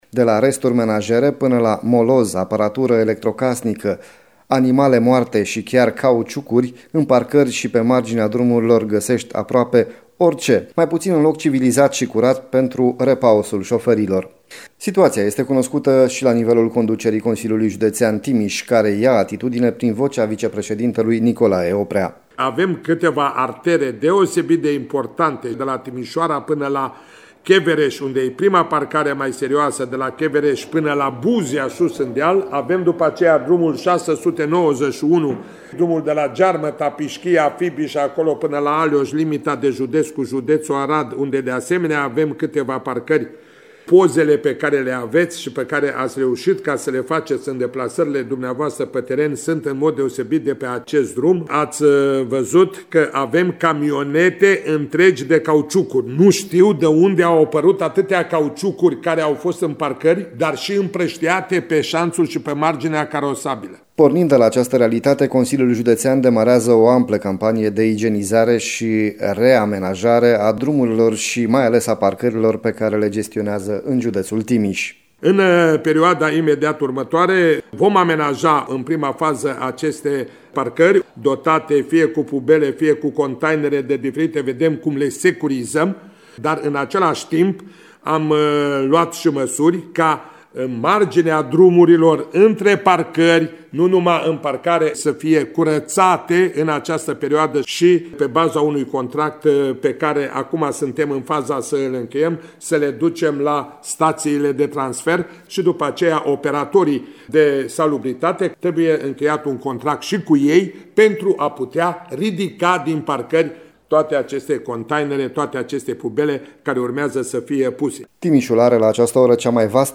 reportajul